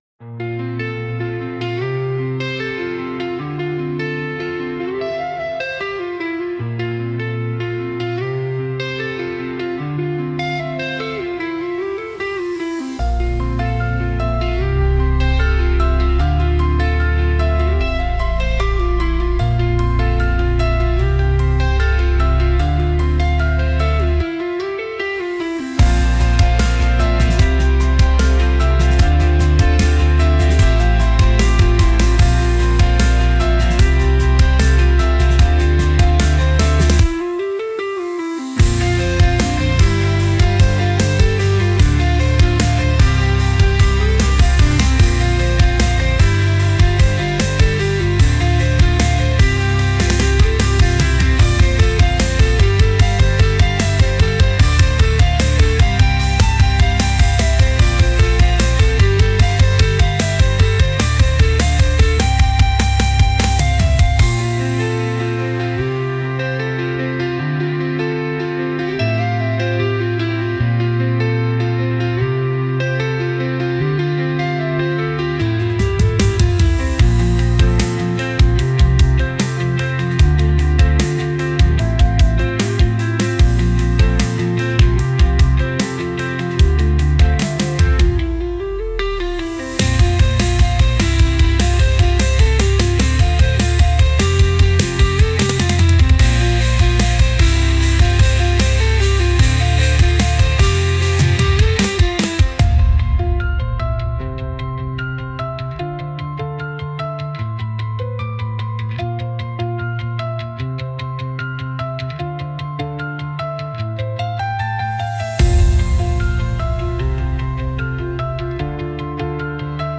Genre: Melancholic Mood: Raw Editor's Choice